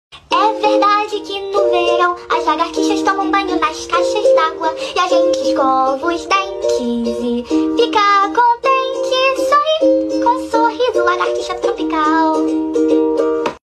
Categoria Efeitos Sonoros